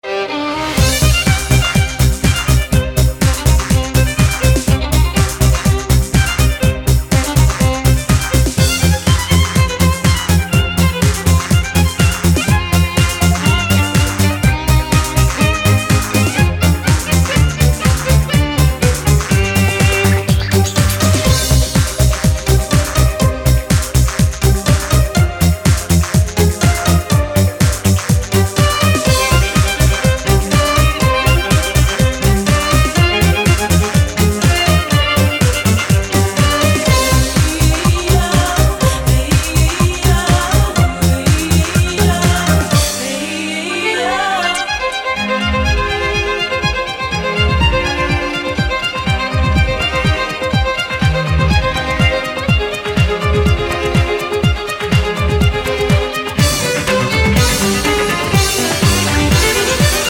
красивые
dance
скрипка
инструментальные
виолончель